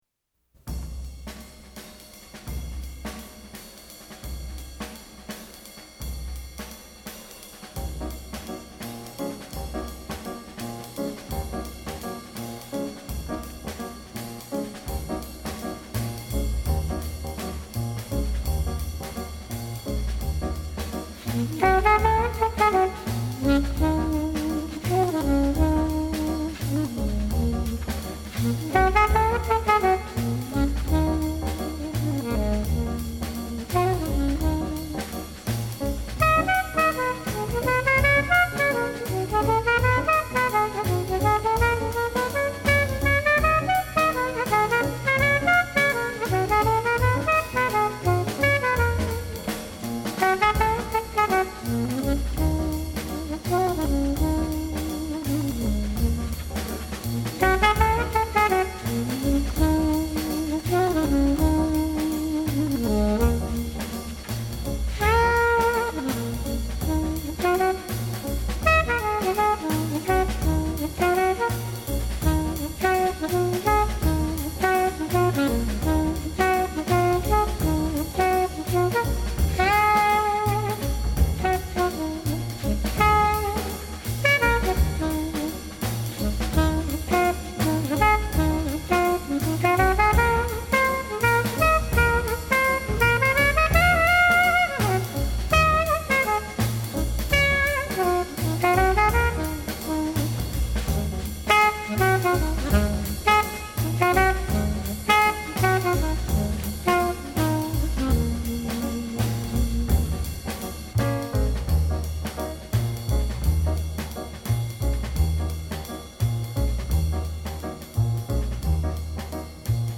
火熱如現場一般的錄音效果，過癮至極！
酷派爵士最經典的代表作